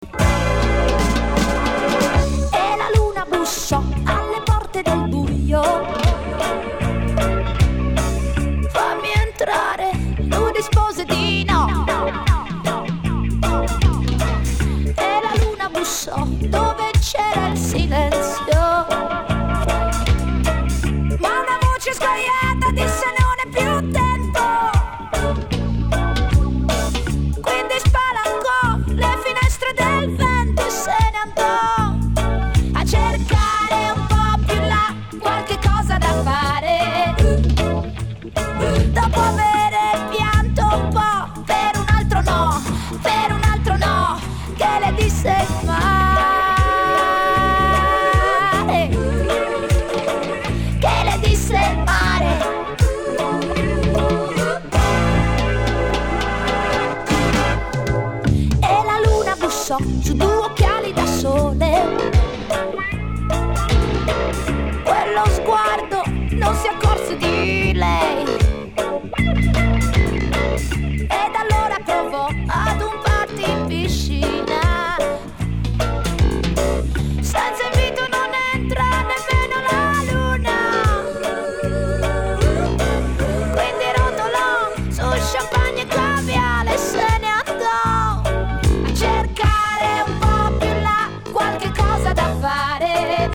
ディスコやブギー好きはもちろん、オブスキュア〜バレアリック、テクノ系のDJにもオススメです！